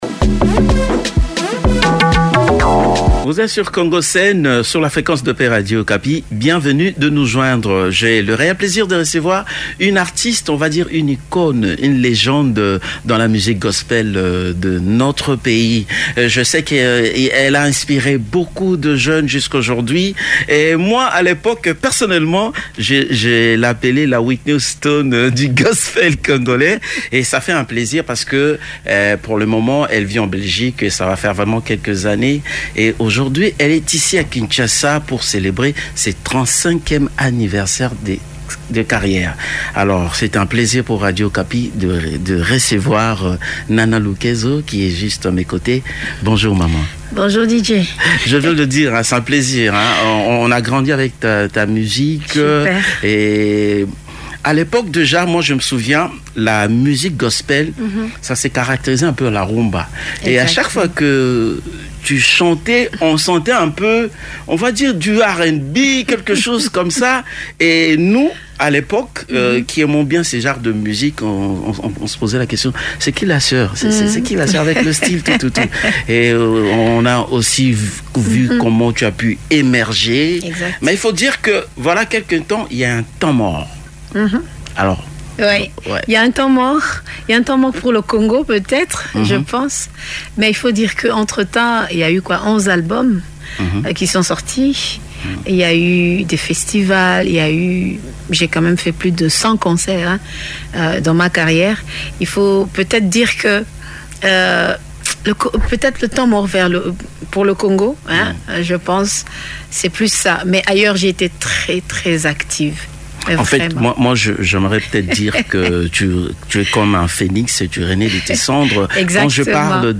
Voici sans plus tarder l’interview exclusive